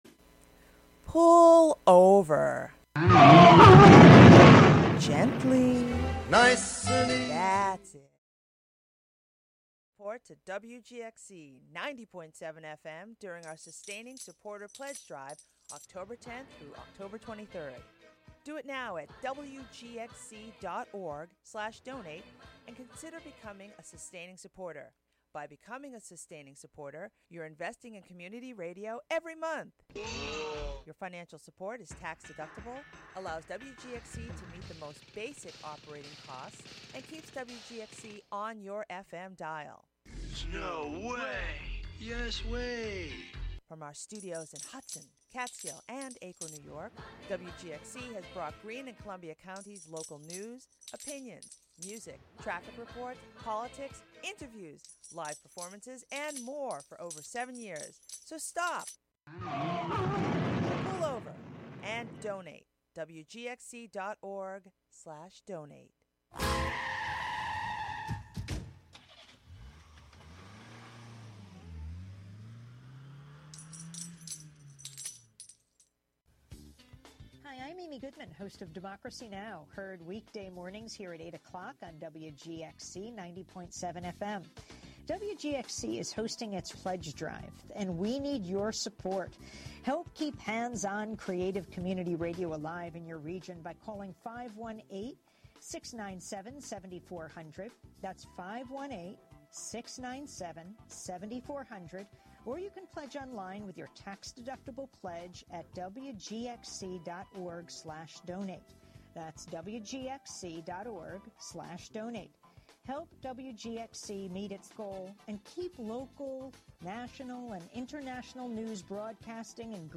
Broadcast live from the Hudson studio.